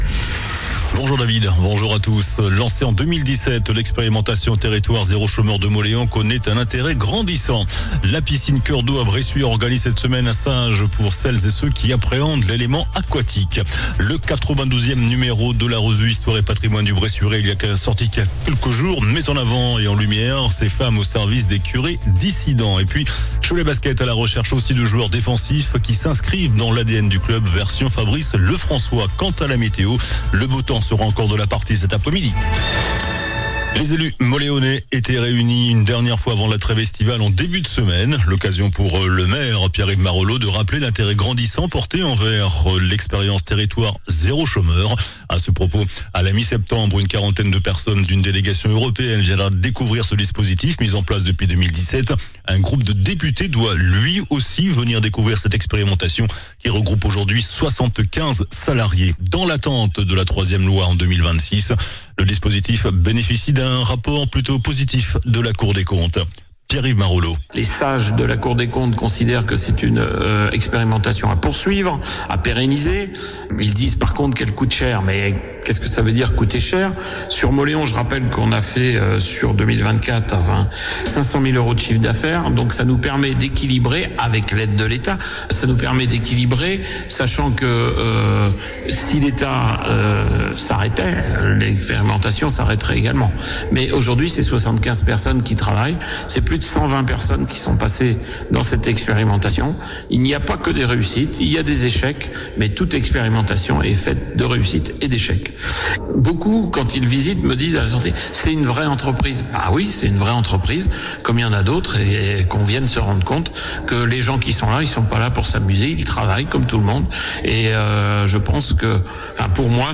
JOURNAL DU JEUDI 03 JUILLET ( MIDI )